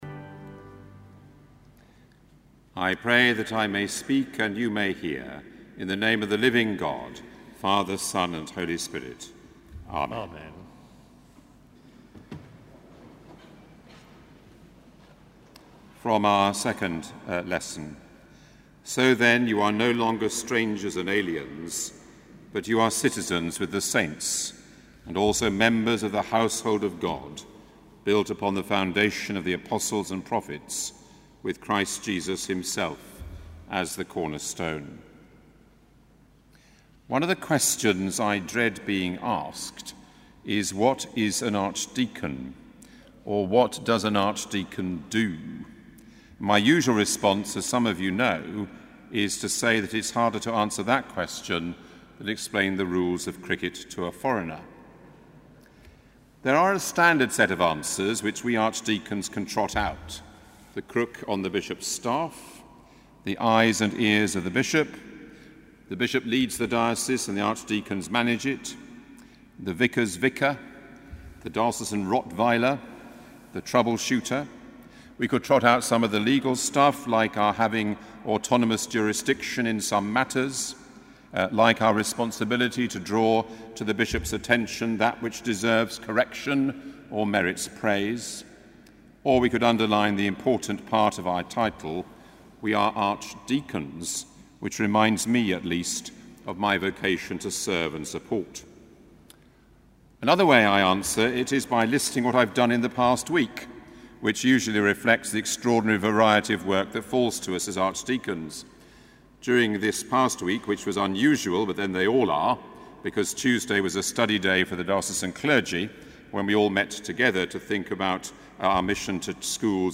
Sermon: Evensong - 11 May 2014